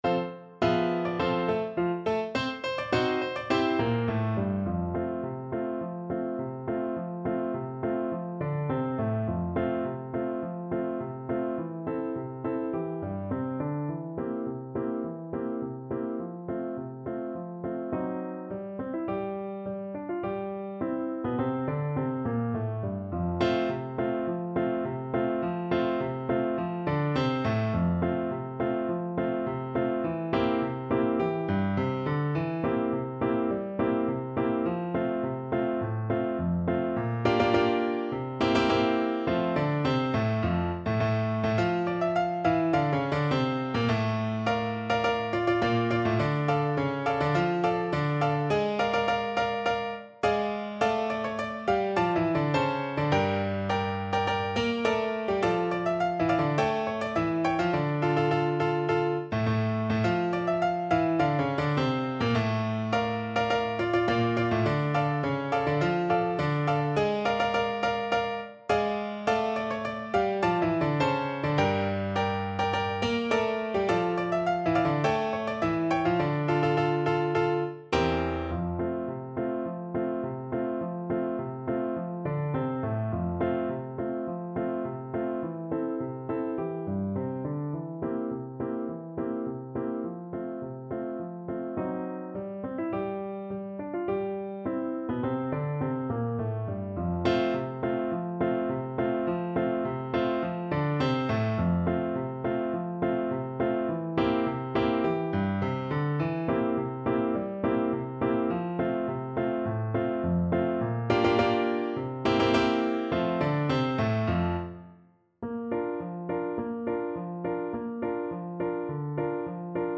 Quick March = c.104
2/2 (View more 2/2 Music)
Classical (View more Classical Tenor Saxophone Music)